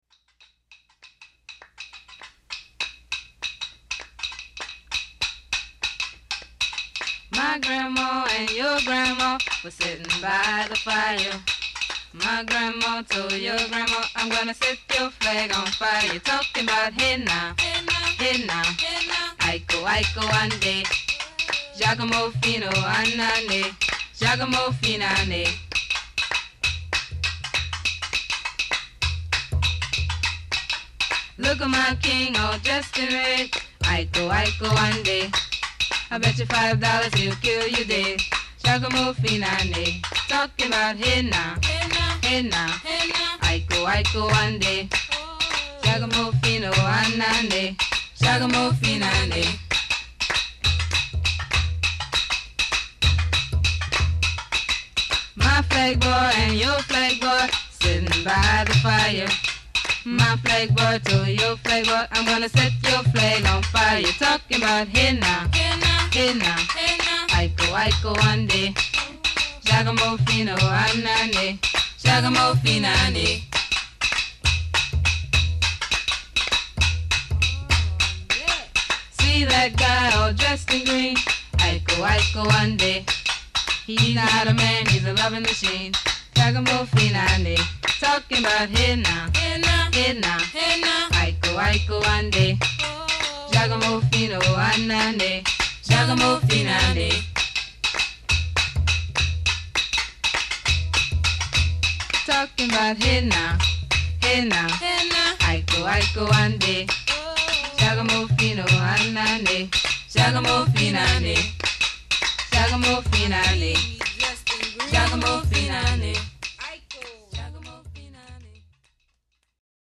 after adding bass and drums